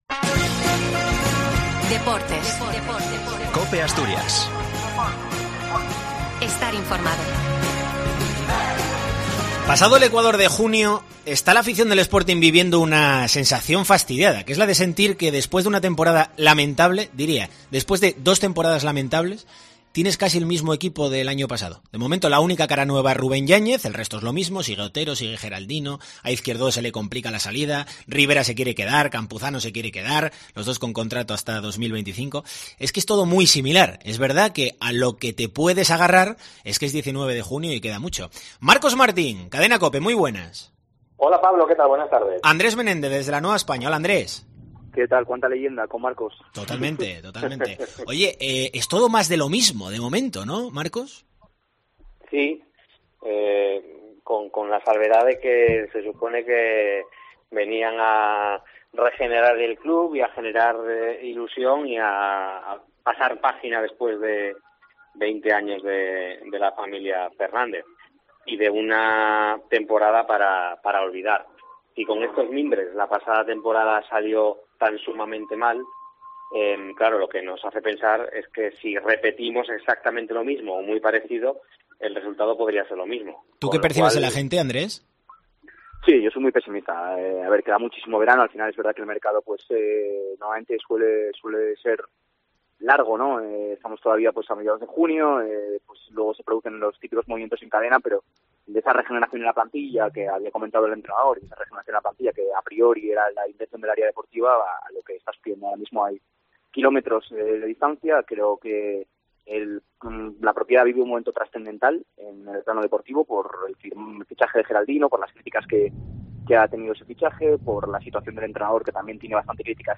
OPINIÓN SPORTING